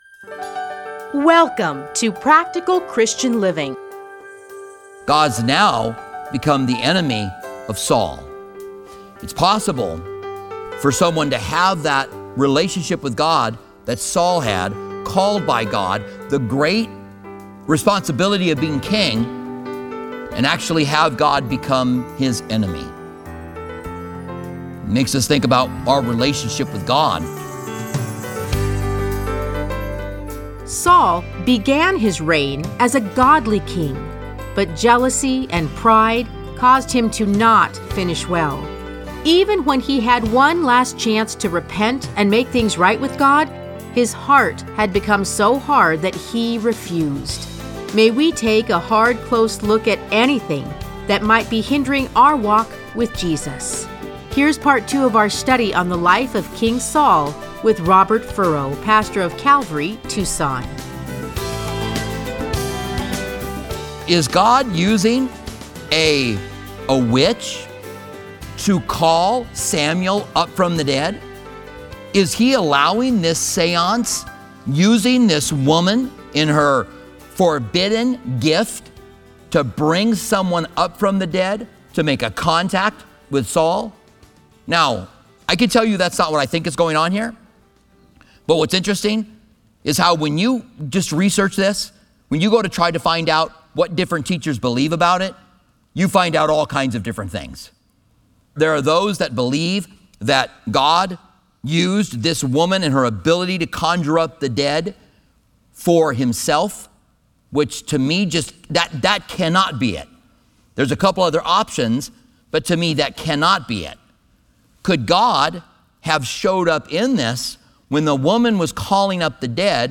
Listen to a teaching from 1 Samuel 31:1-13.